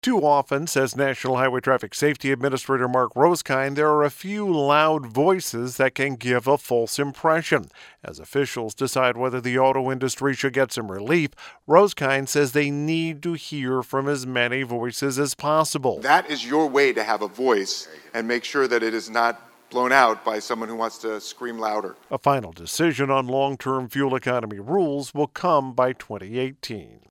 AutoBeat Reporter